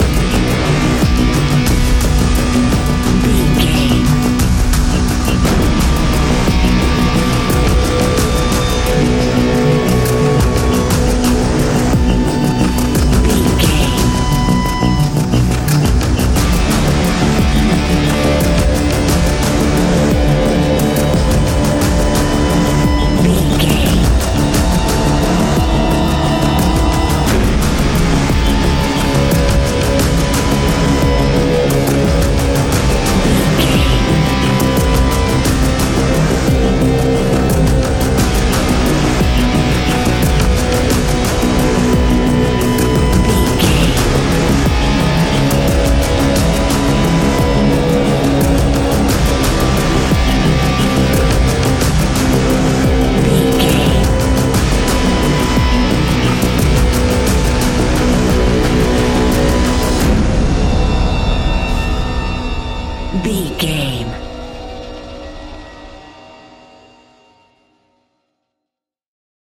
Ionian/Major
B♭
industrial
dark ambient
EBM
experimental
synths